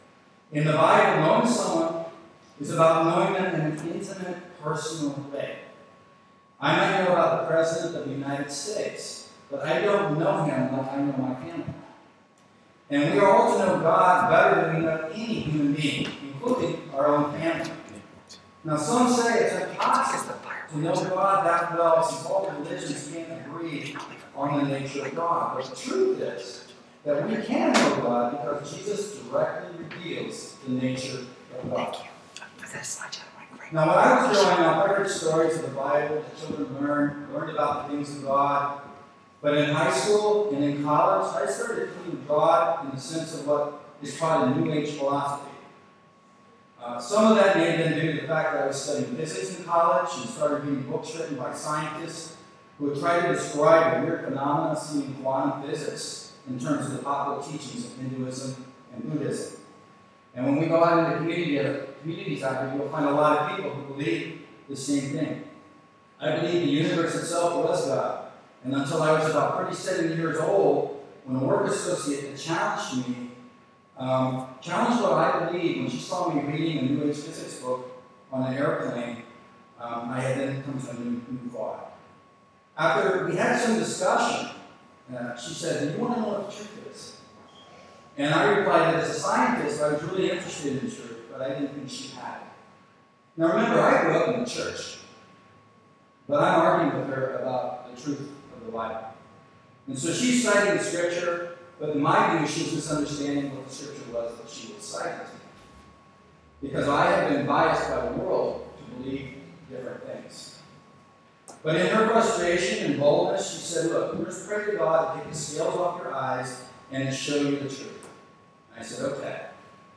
JULY 25 SERMON
(GUEST SPEAKER)